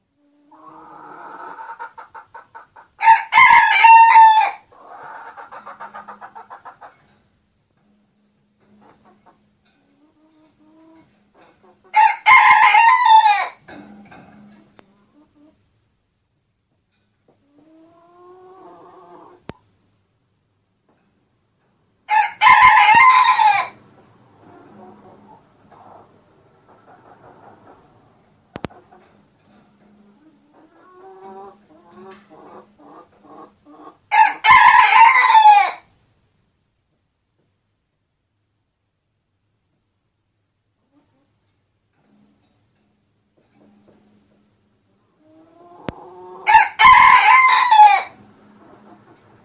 I've got the Chantys in my one sunroom and they have calmed down quite a bit since they were first brought home. beautiful birds and hes got one heck of a crow. you wouldnt know by his crow how small he is. its the best crow I've ever heard from any bantam.